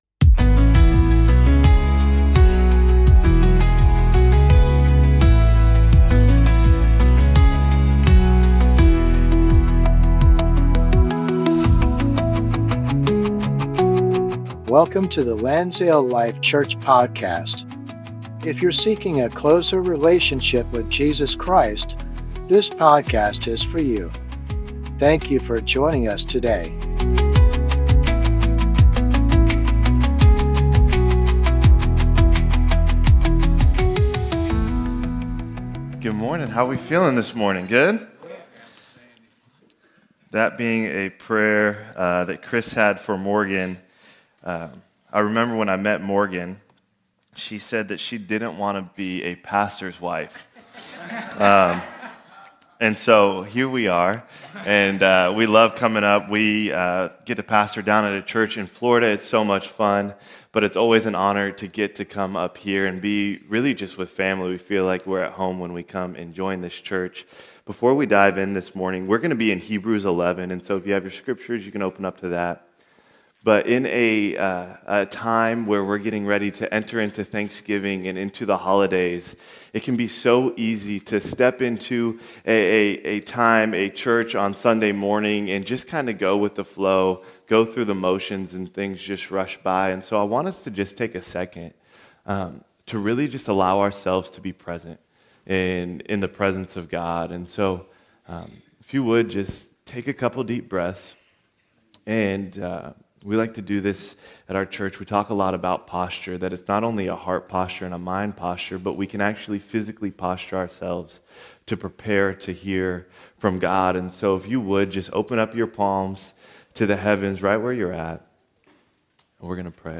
Sunday Service - 2025-11-23